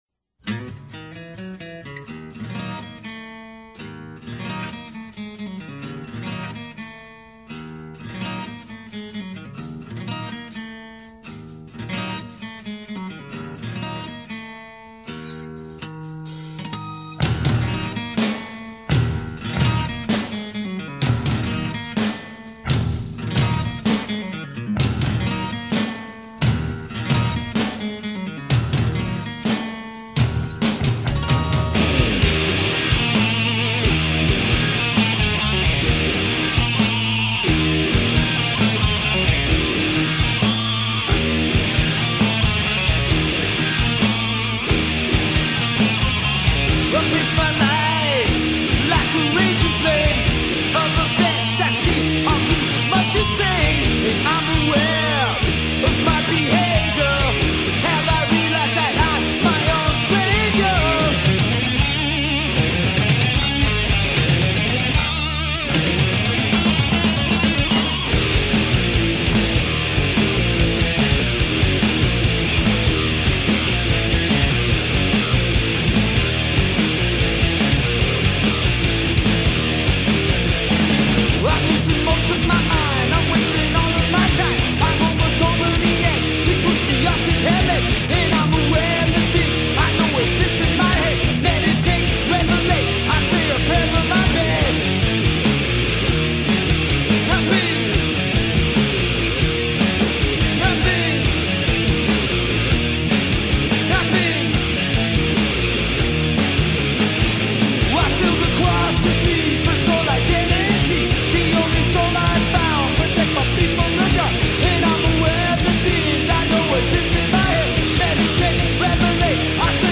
موزیک خارجی Metal